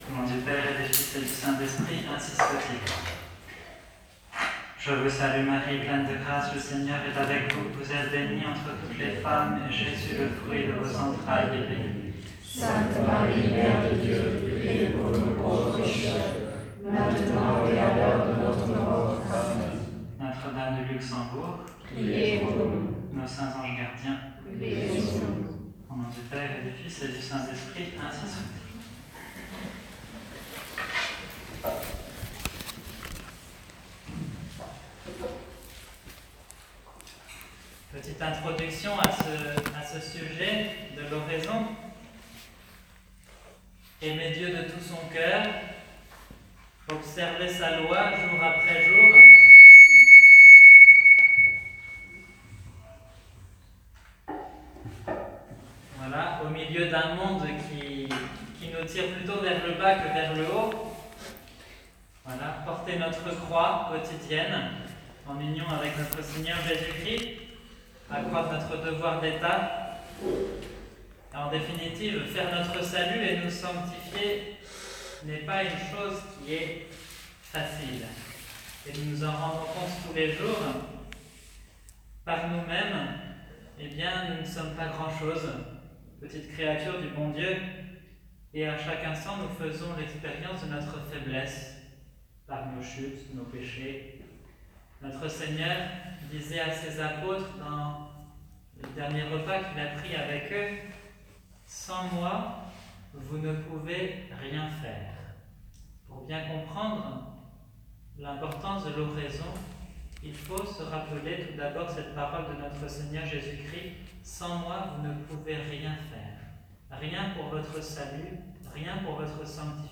Conférence sur l’oraison